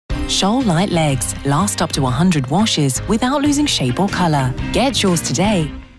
Native speaker Kobieta 30-50 lat
Spot reklamowy